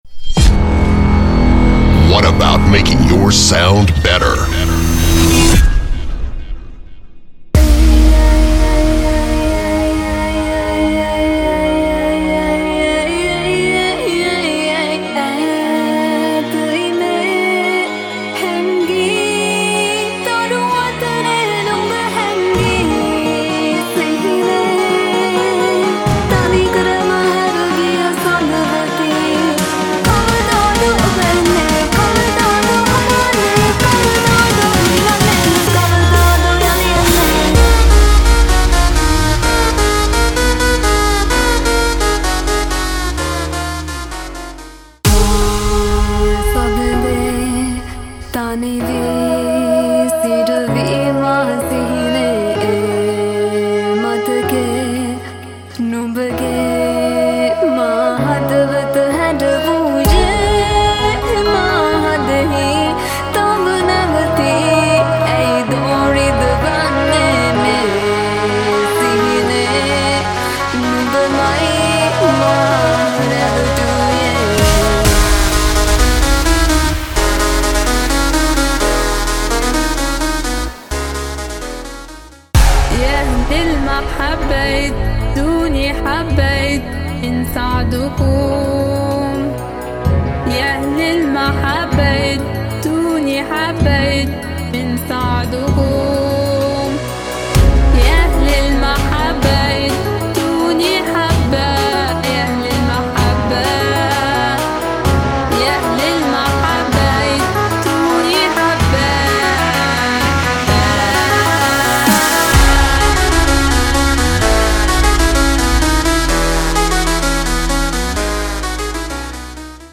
• 23个声乐制作套件
• 227人声循环
• 85人声合唱
• 95个鼓循环
• 35低音循环